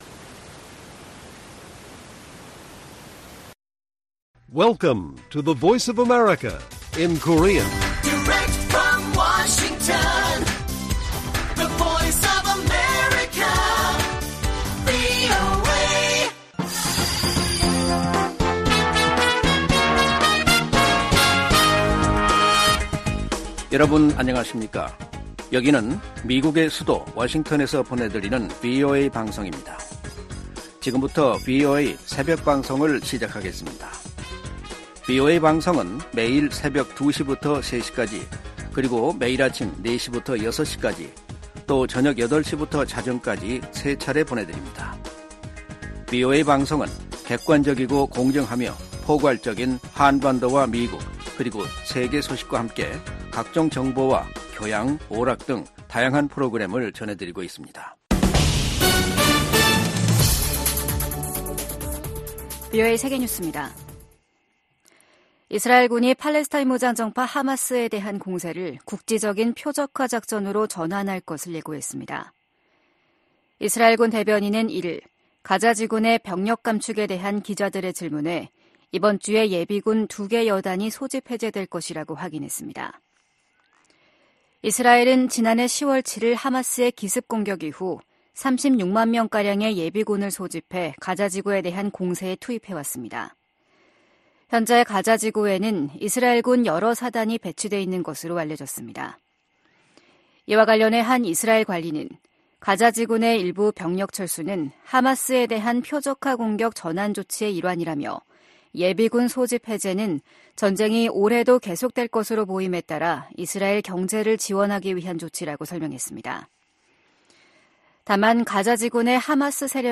VOA 한국어 '출발 뉴스 쇼', 2024년 1월 3일 방송입니다. 미 국무부가 김정은 북한 국무위원장의 추가 위성 발사 예고에 대륙간탄도미사일(ICBM) 개발과 다름없는 것이라는 입장을 밝혔습니다. 한국이 2년간의 유엔 안전보장이사회 비상임이사국 활동을 시작했습니다. 북한과 러시아가 미국의 금융 제재망을 우회해 무기 거래를 지속할 우려가 있다고 미국 전문가들이 지적했습니다.